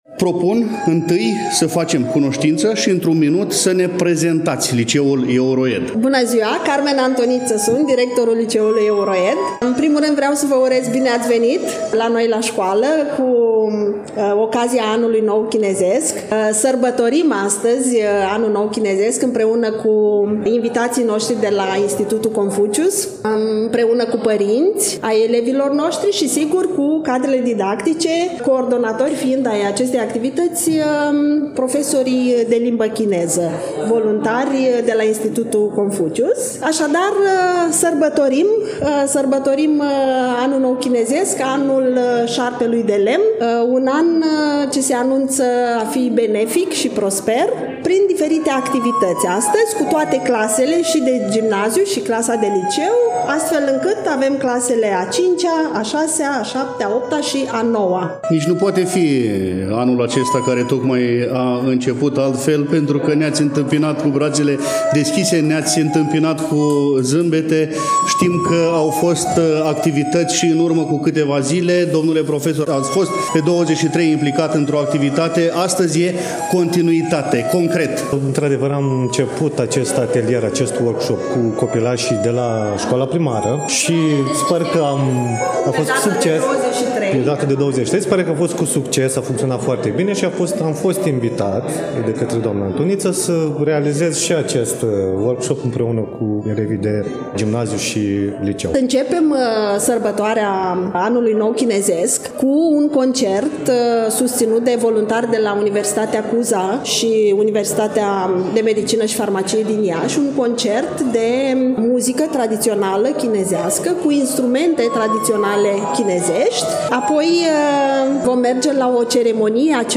Dacă în două ediții trecute ale emisiunii noastre am vorbit despre Anul Nou Chinezesc, cu accent pe câteva activități culturale organizate cu acest prilej atât în incinta Fundației EuroEd din Iași, cât și în incinta Universității de Medicină și Farmacie „Gr. T. Popa” Iași, astăzi continuăm a difuza câteva înregistrări din timpul sărbătorii pe care am amintit-o.